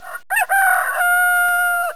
Rooster